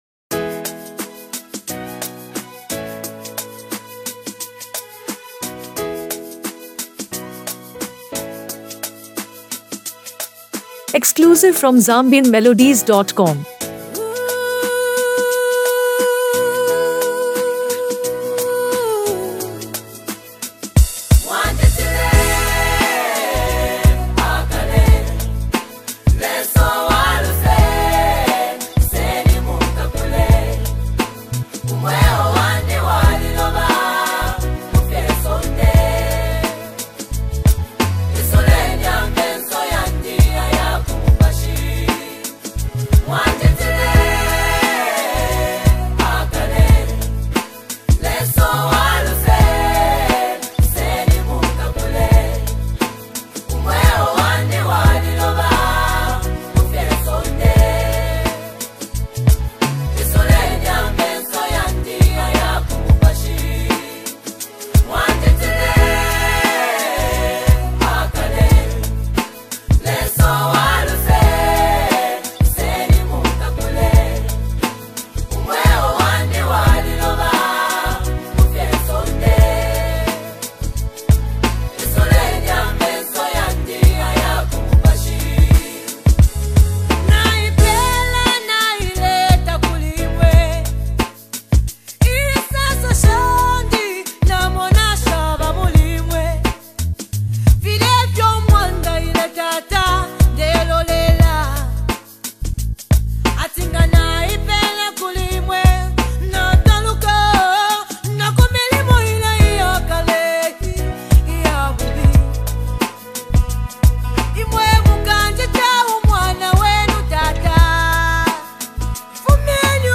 Zambian gospel music
Known for her soul-lifting voice and deep lyrical content
Through heartfelt lyrics and captivating vocals